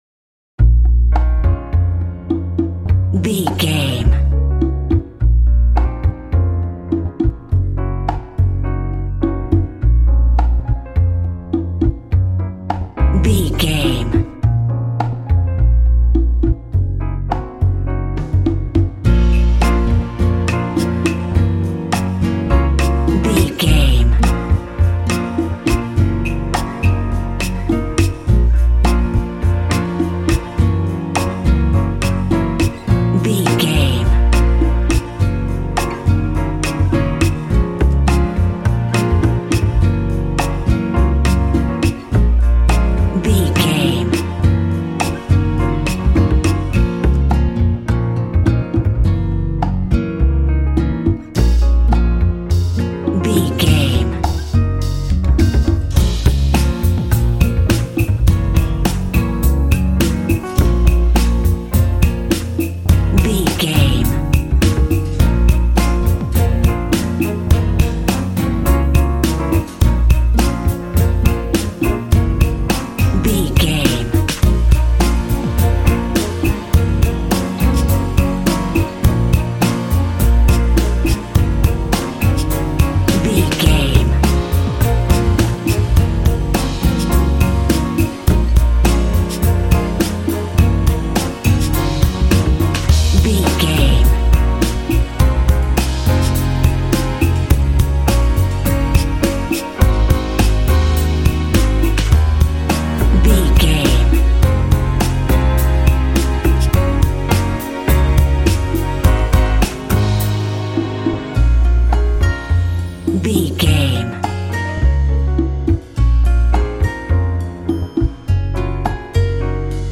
Aeolian/Minor
B♭
groovy
percussion
acoustic guitar
bass guitar
drums
strings
conga
smooth latin jazz